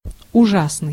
Ääntäminen
Vaihtoehtoiset kirjoitusmuodot (rikkinäinen englanti) turrible Synonyymit appalling frightful hideous horrendous hellish Ääntäminen UK : IPA : /ˈtɛ.ɹə.bl̩/ US : IPA : [ˈtɛ.ɹə.bl̩] UK : IPA : ˈtɛ.ɹɪ.bl̩/